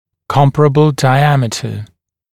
[‘kɔmpərəbl daɪ’æmɪtə][‘компэрэбл дай’эмитэ]схожий диаметр